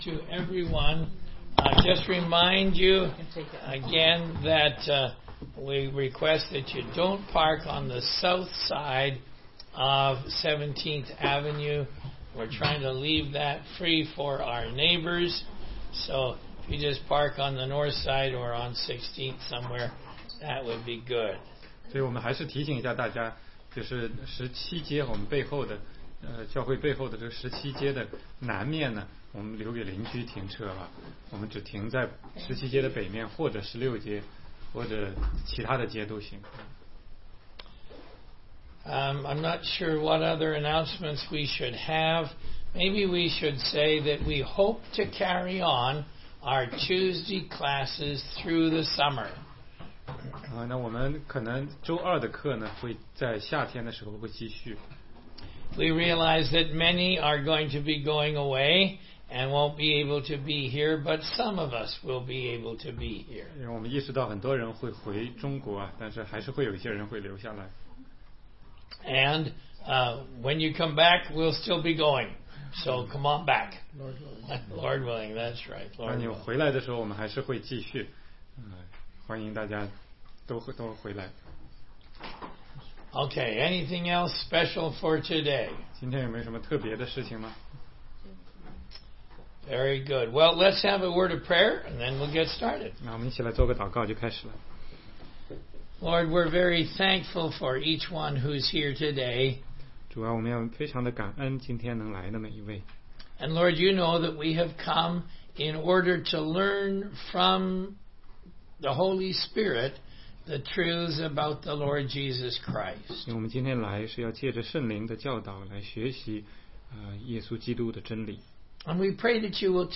16街讲道录音 - 使徒行传7章54节-8章25节